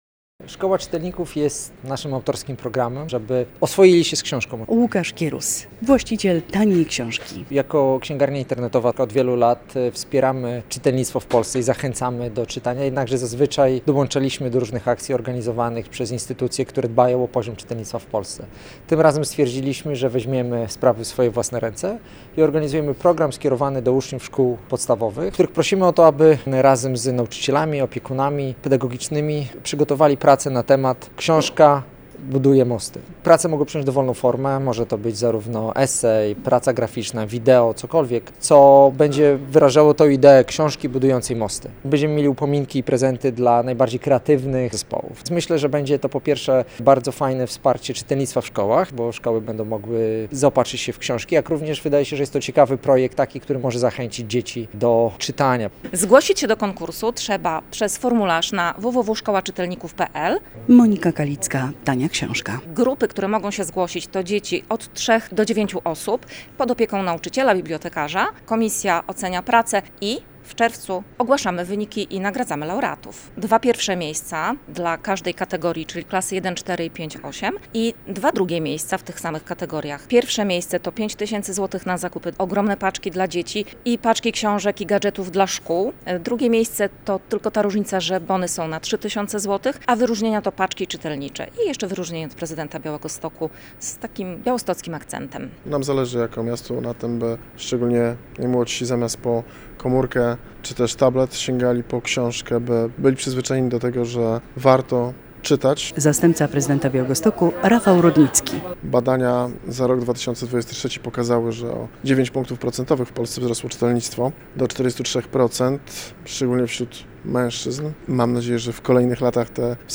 O akcji mówią organizatorzy!